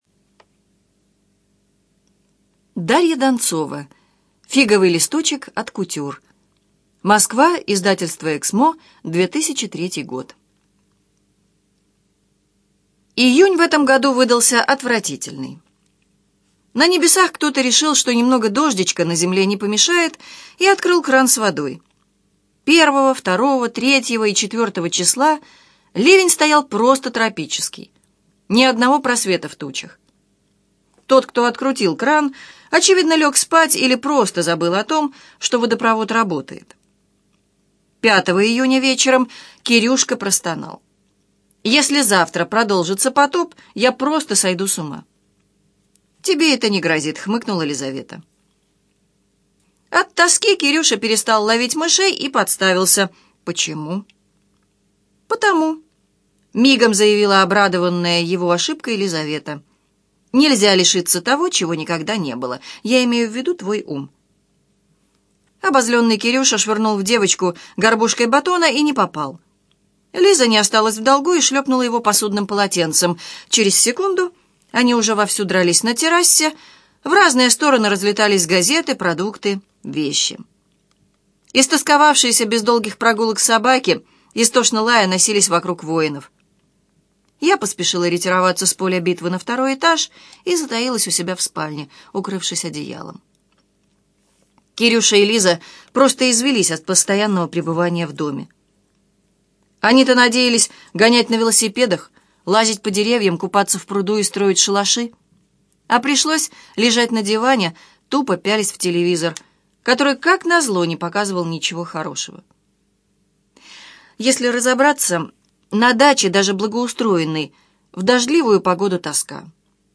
Аудиокнига Фиговый листочек от кутюр - купить, скачать и слушать онлайн | КнигоПоиск